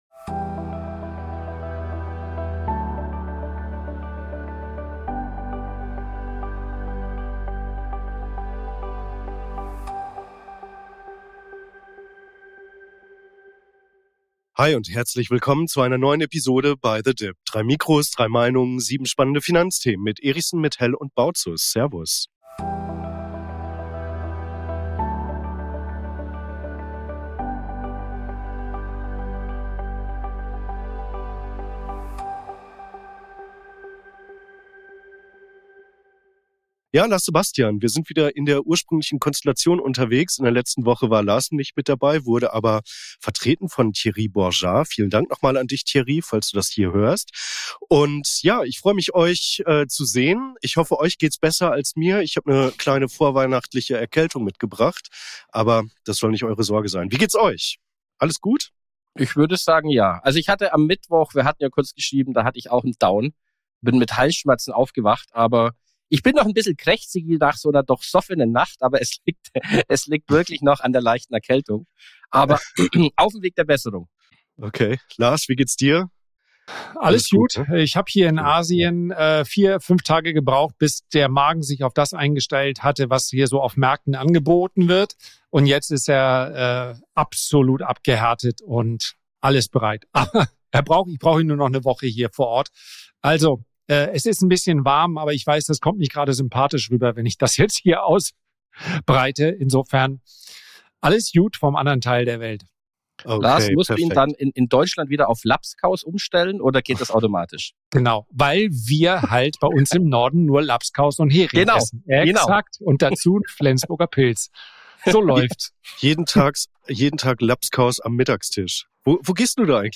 Auch diese Woche begrüßen wir euch unter dem Motto „3 Mikrofone, 3 Meinungen“ zu den folgenden Themen in dieser Ausgabe: Jetzt werden die Weichen für 2026 gestellt!